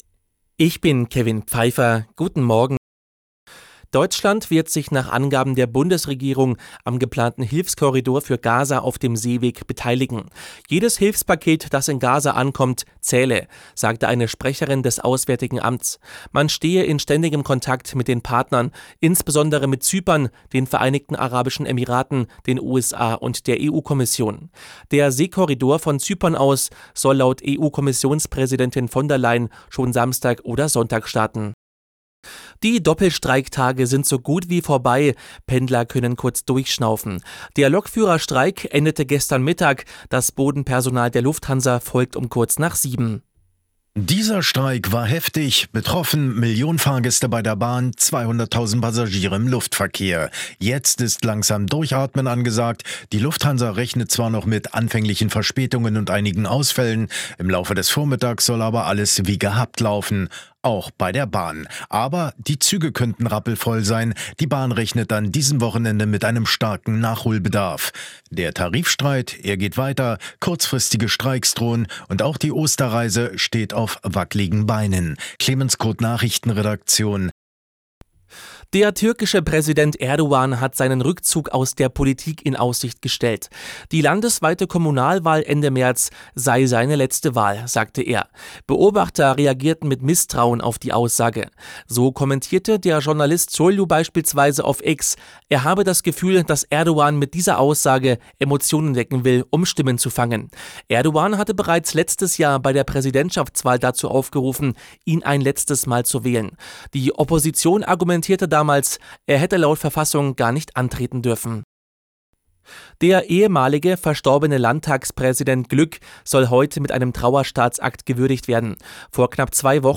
Die Arabella Nachrichten vom Samstag, 9.03.2024 um 10:06 Uhr - 09.03.2024